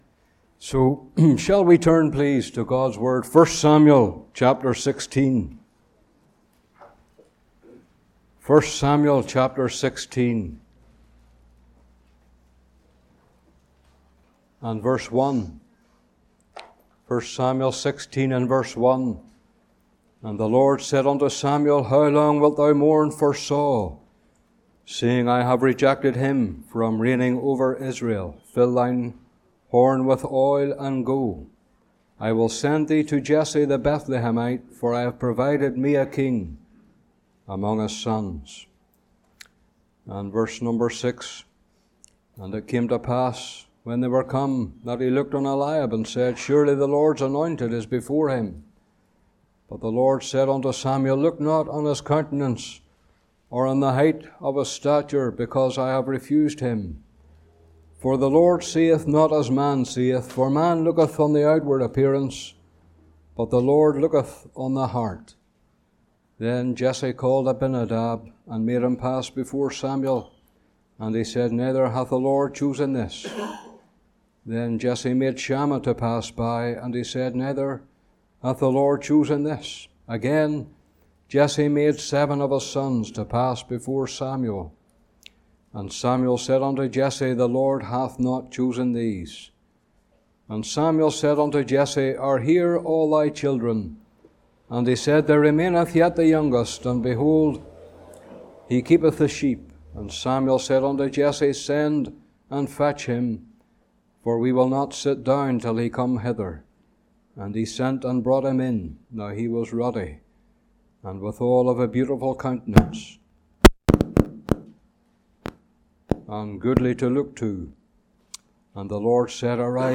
Location: Cooroy Gospel Hall (Cooroy, QLD, Australia)